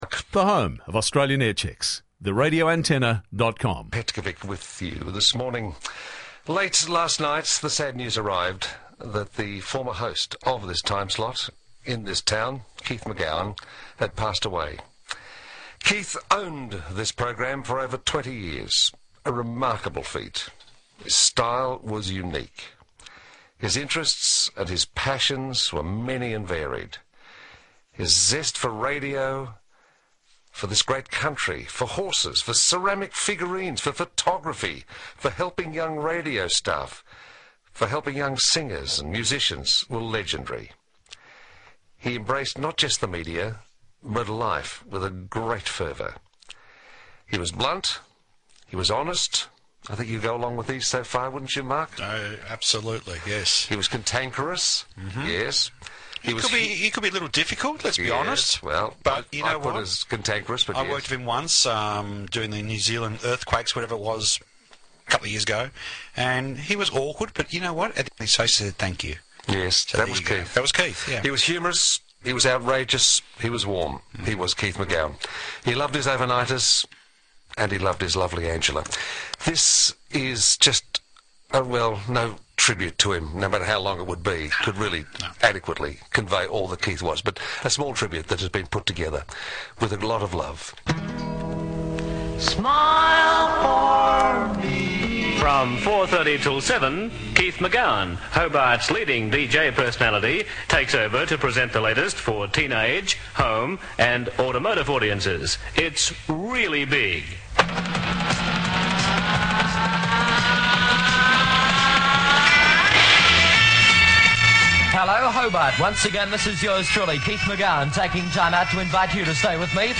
RA Aircheck